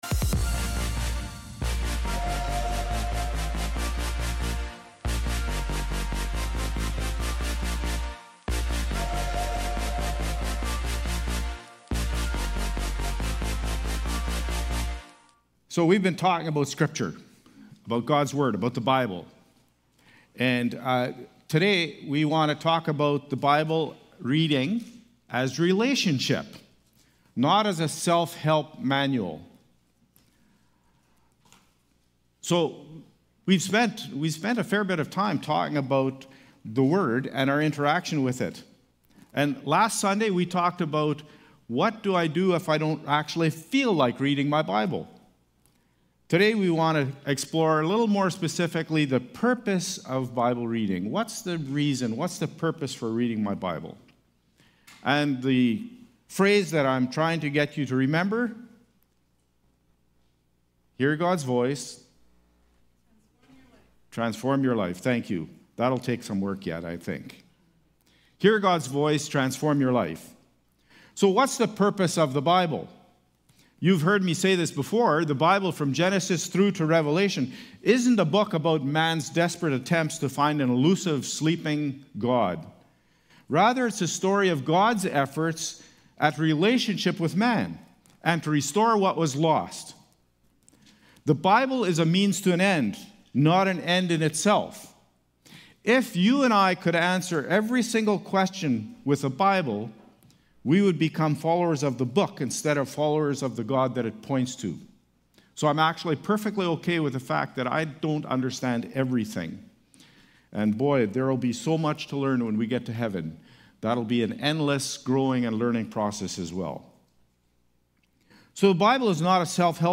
May-25-Service-Audio.mp3